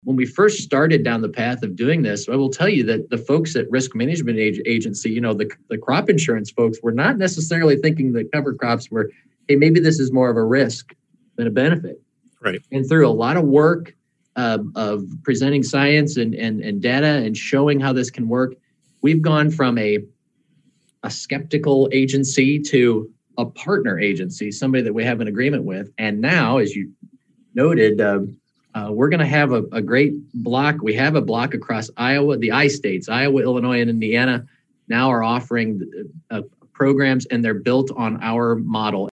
Iowa Secretary of Agriculture Mike Naig was a special guest for the Iowa Farmers Union?s recent virtual lunch and learn program.